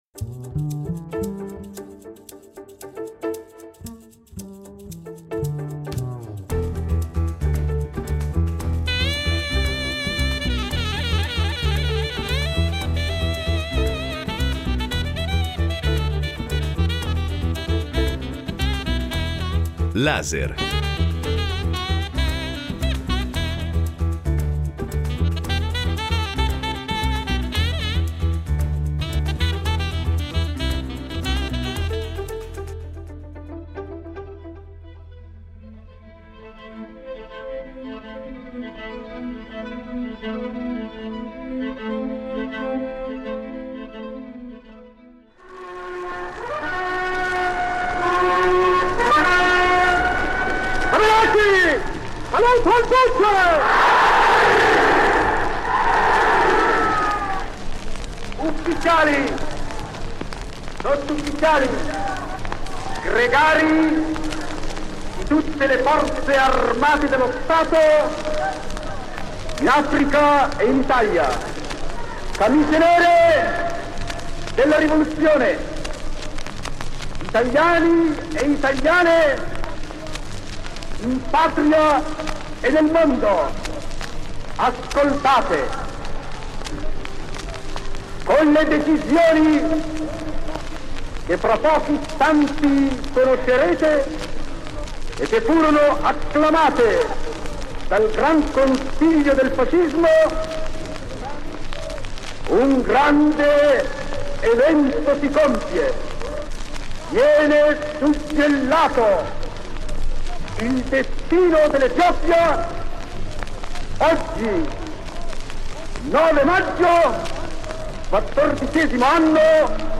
Ne parliamo con due storici del fascismo: